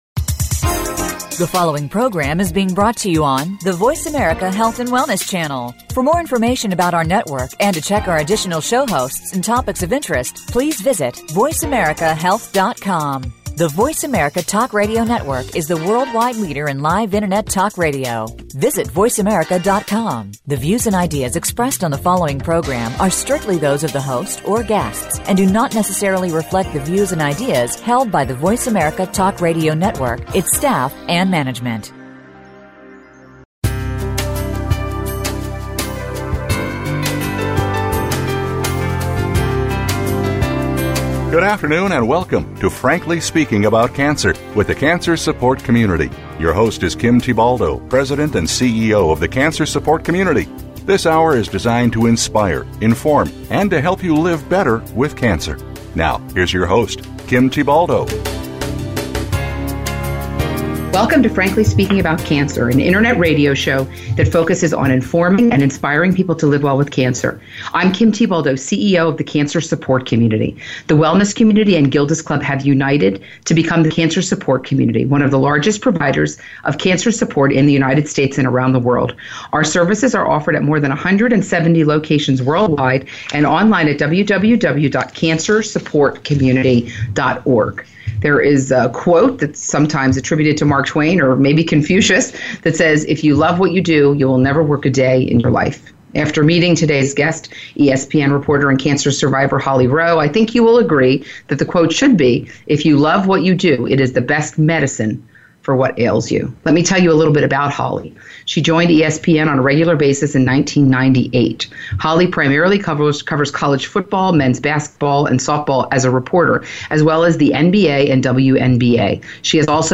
Holly Rowe's Siren Song: A Conversation about Sports, Work, and Cancer